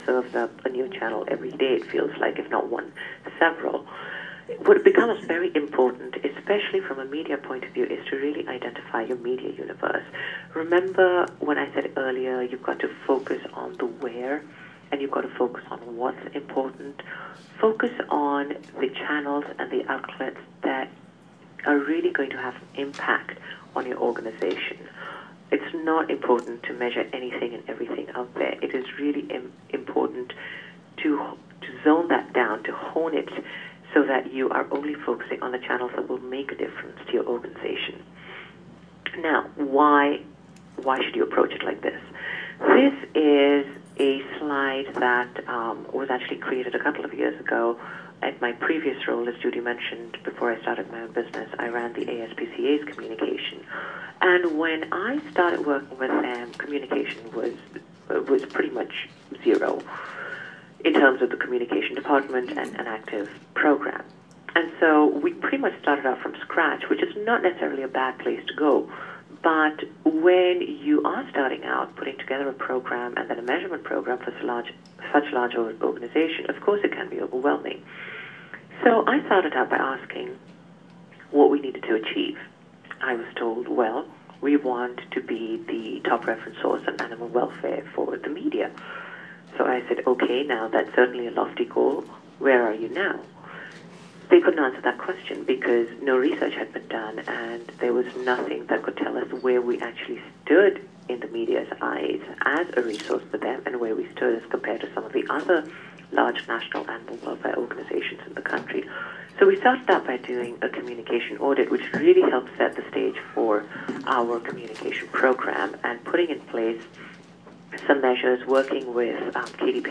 PRSA teleseminar on measuring PR effectiveness